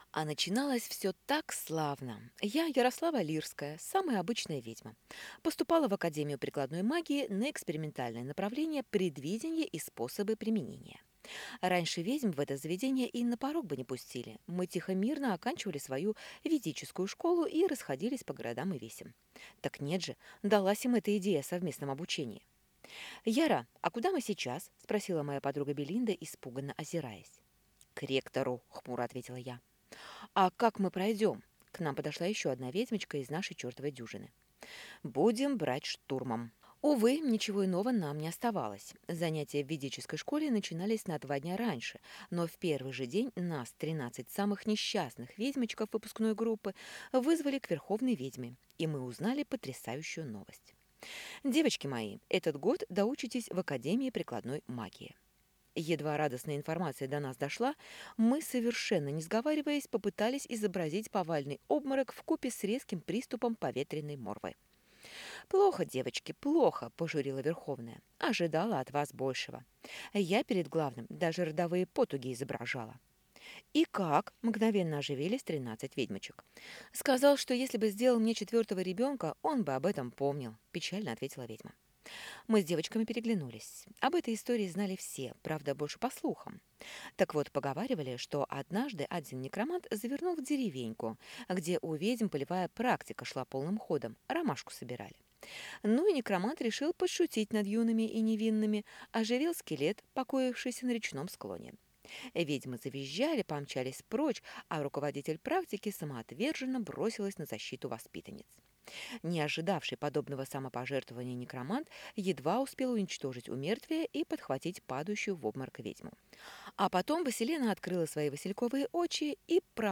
Аудиокнига Мой личный враг - купить, скачать и слушать онлайн | КнигоПоиск